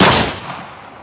gun1.ogg